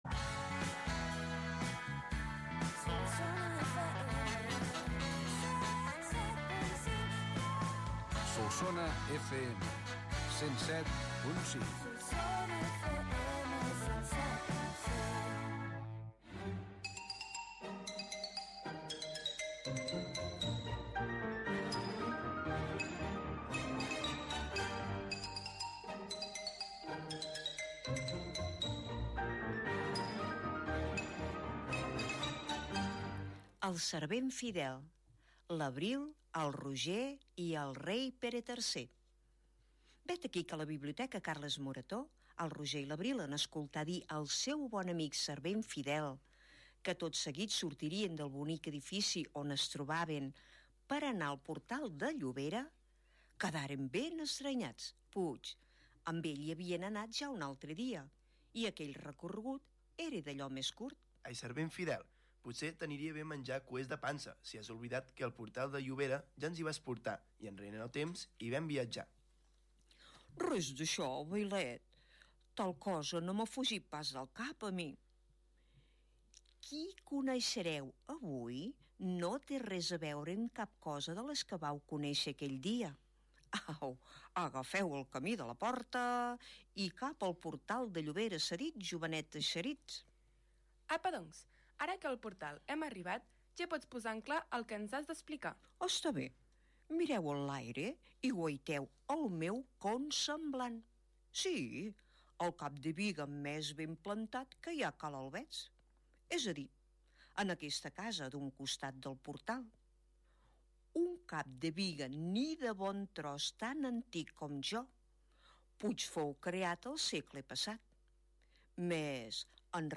emès per l’emissora de ràdio Solsona FM el dia 18 d octubre de 2022